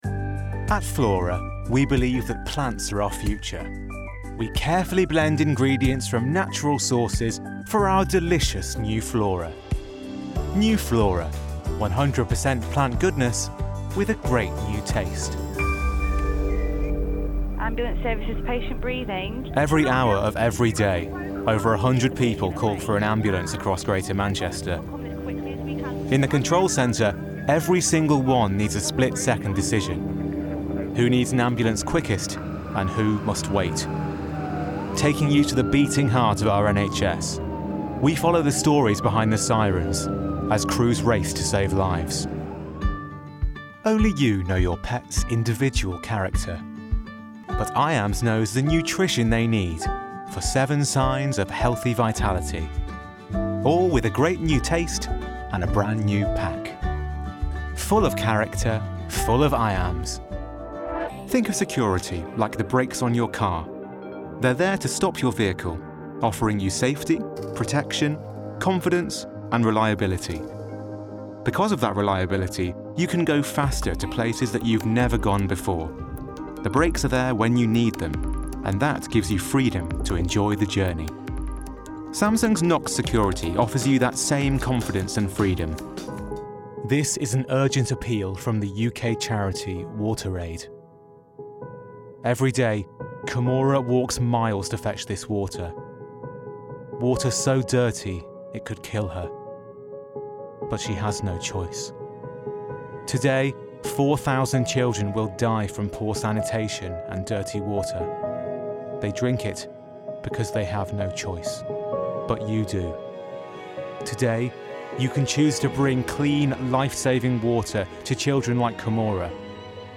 Showreel
Male / 30s, 40s / English / Northern